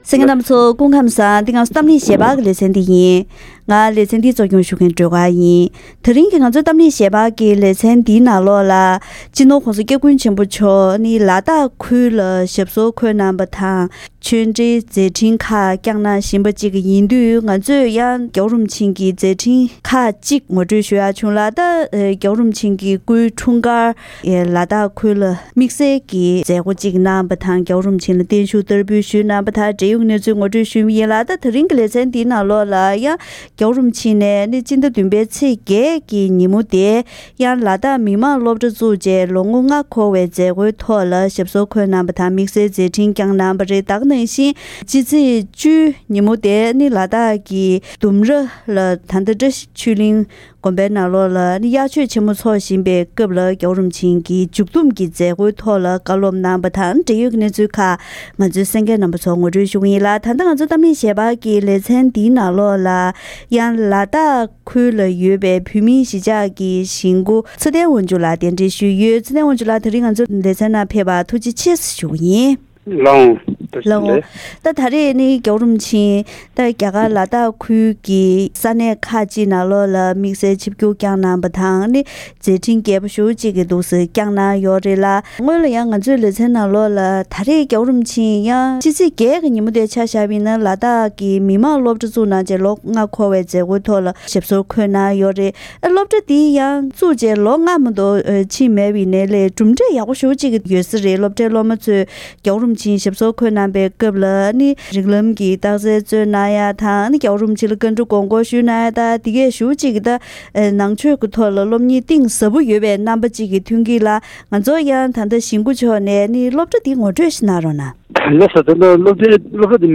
༄༅། །ཐེངས་འདིའི་གཏམ་གླེང་ཞལ་པར་ལེ་ཚན་ནང་། སྤྱི་ནོར་༸གོང་ས་༸སྐྱབས་མགོན་ཆེན་པོ་མཆོག་ནས་ལ་དྭགས་ཁུལ་དུ་ཆོས་འབྲེལ་གནང་སྐབས་རྨོངས་དད་སྤང་ནས་དུས་རབས་༢༡པའི་ནང་པ་ཞིག་བྱ་དགོས་པ་དང་། ཁ་ཆེའི་ཆོས་པ་དང་མཐུན་ལམ་དགོས་སྐོར་བཀའ་སློབ་གནང་ཡོད་པའི་ཞིབ་ཕྲའི་གནས་ཚུལ་ཞིག་གསན་རོགས་གནང་།